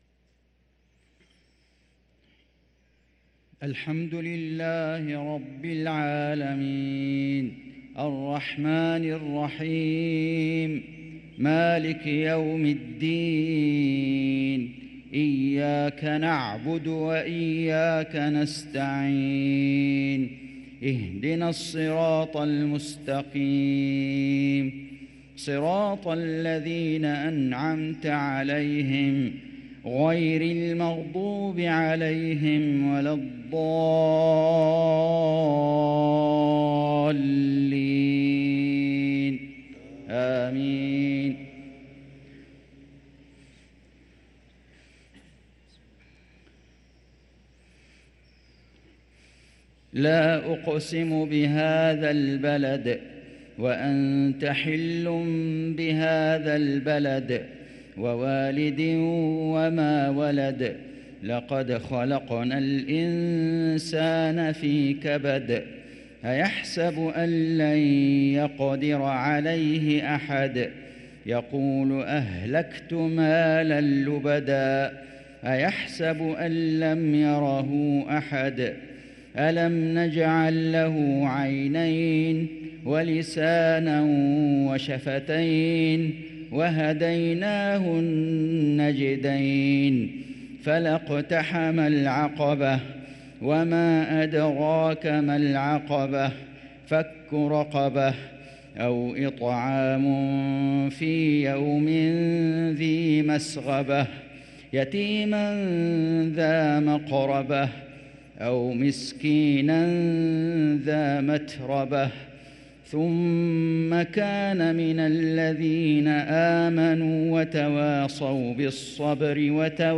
صلاة المغرب للقارئ فيصل غزاوي 15 رجب 1444 هـ
تِلَاوَات الْحَرَمَيْن .